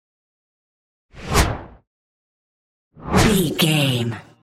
Whoosh fast x2
Sound Effects
Fast
futuristic
intense
whoosh